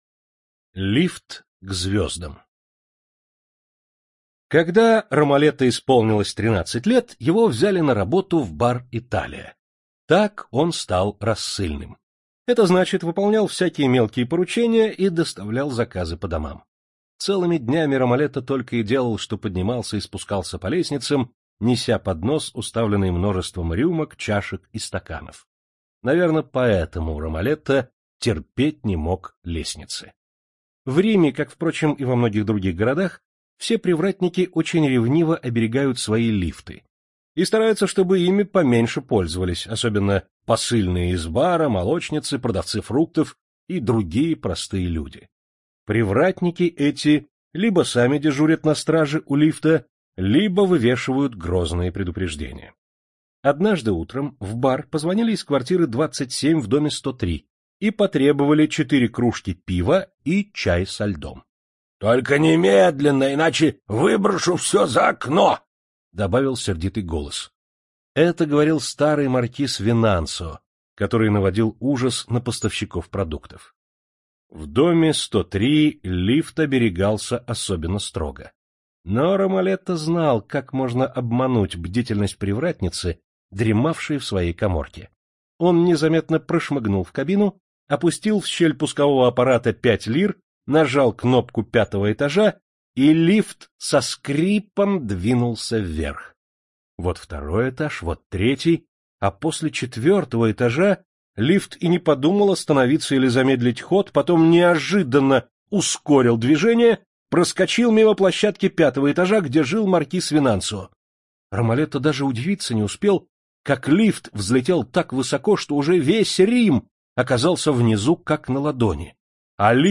На данной странице вы можете слушать онлайн бесплатно и скачать аудиокнигу "Лифт к звёздам" писателя Джанни Родари.